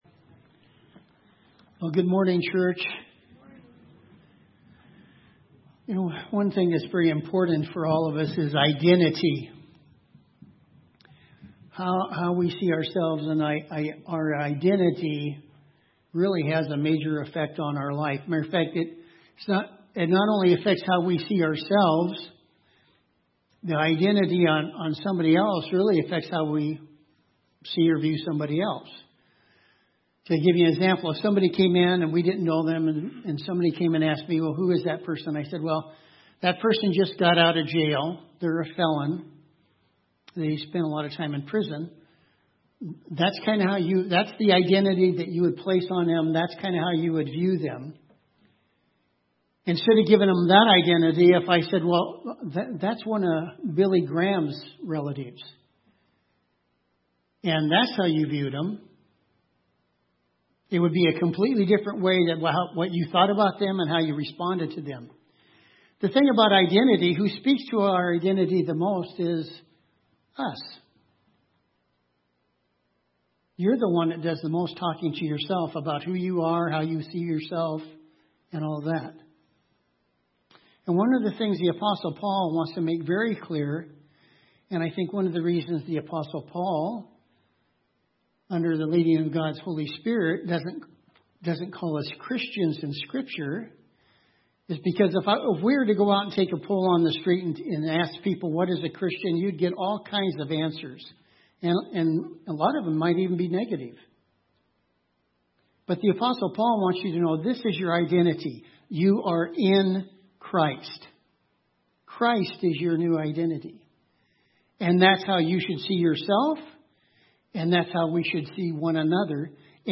Audio Sermons | Tonasket Free Methodist Church